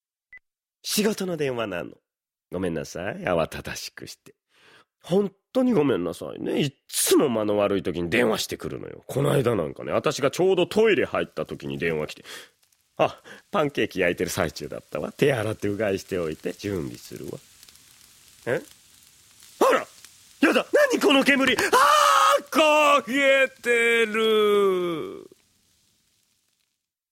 オネェCD ～となりの世話焼き・カオルちゃん～｜サンプルボイス01